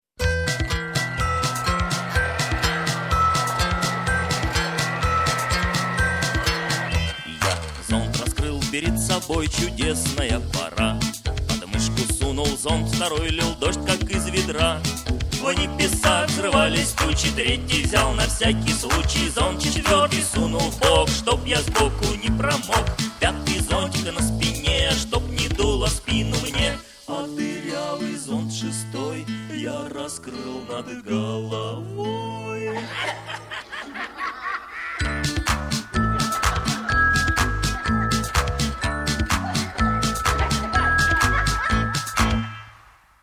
песни для детей